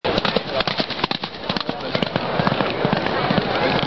Horse Galopp Race Pferderennen 01 Botão de Som